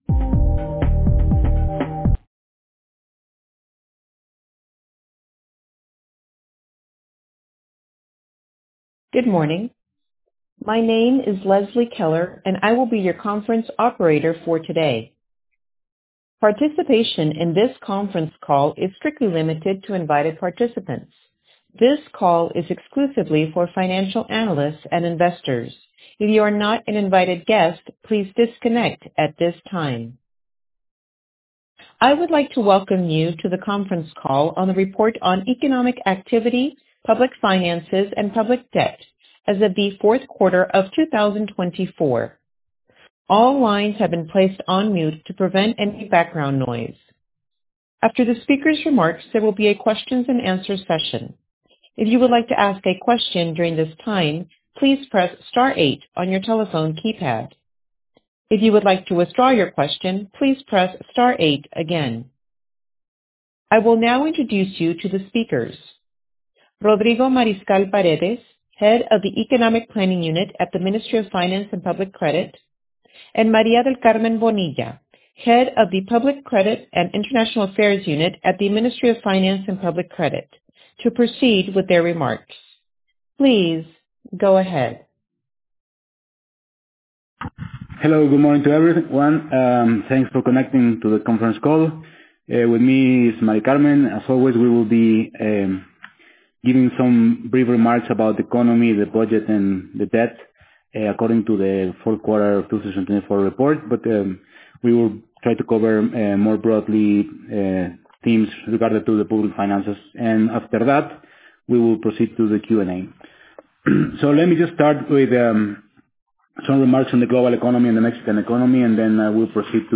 2024 Press Release Complete document Audio 1 Fourth Quarter PDF PDF AUDIO Third Quarter PDF PDF AUDIO Second Quarter PDF PDF AUDIO First Quarter PDF PDF AUDIO 1 The audio corresponds to the conference call for investors organized after the publication of the Report.